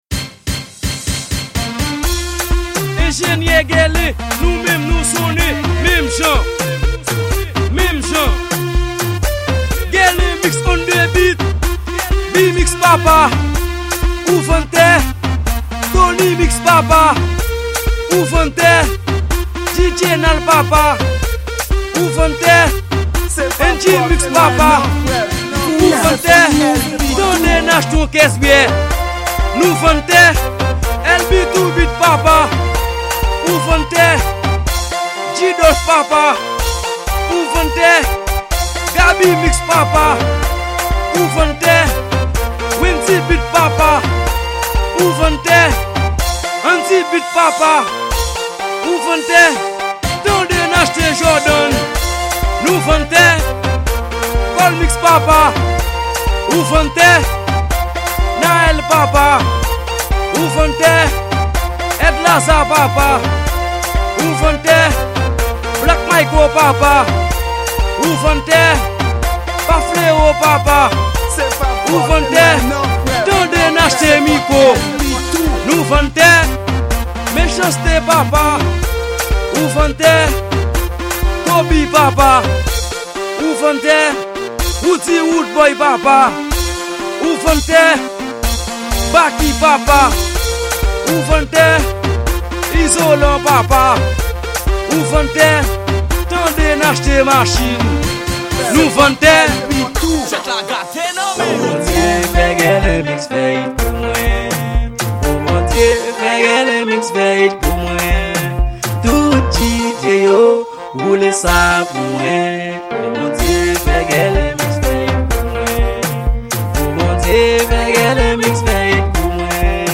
Genre: Raboday